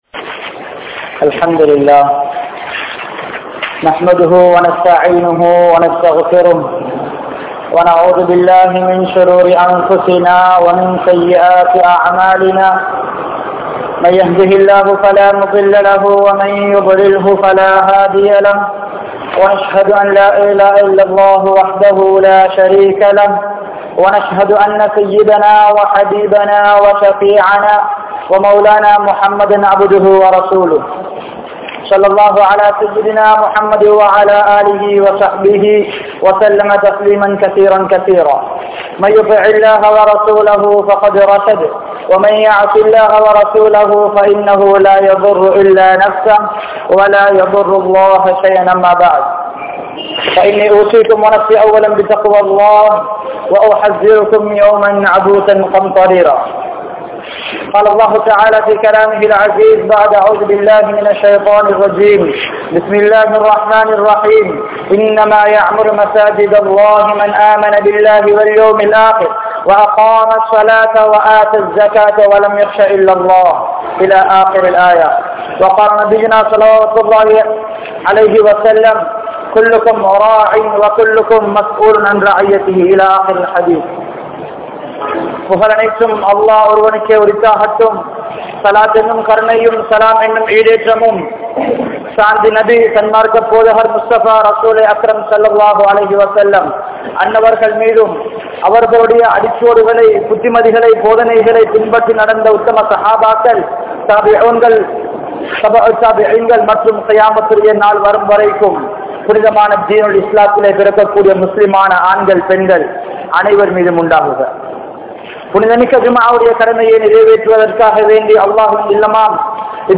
Amaanitham (அமானிதம்) | Audio Bayans | All Ceylon Muslim Youth Community | Addalaichenai
Al Hudha Jumua Masjidh